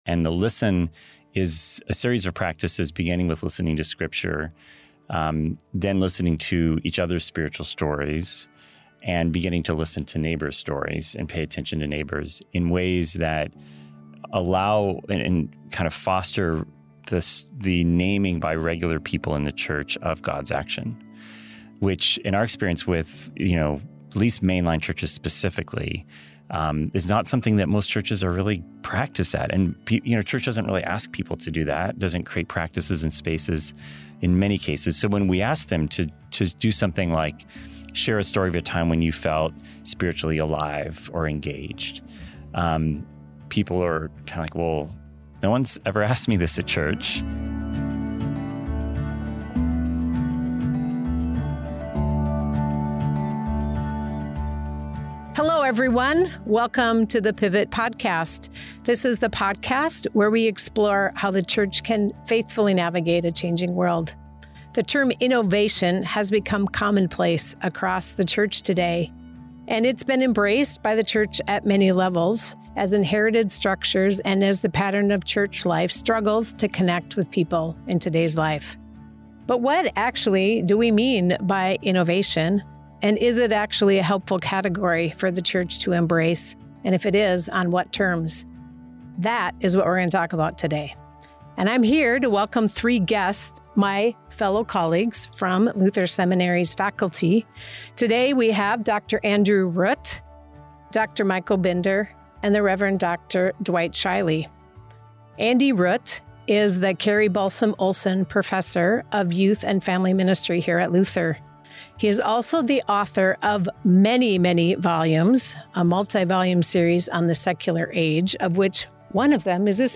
Their spirited conversation reveals both cautions and possibilities for churches seeking to thrive beyond traditional growth metrics.